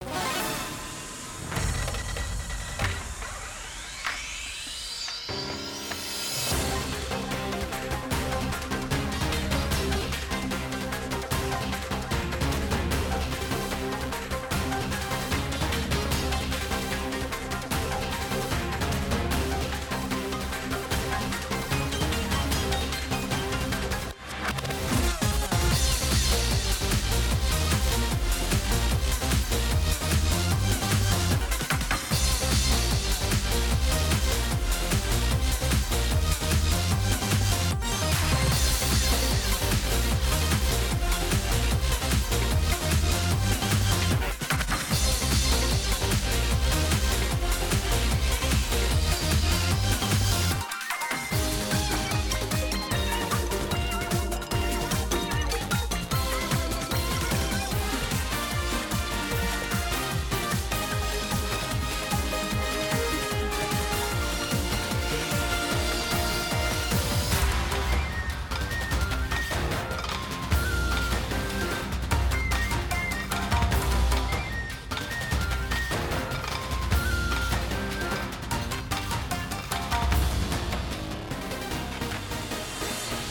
Application of EBU R 128 to all BGM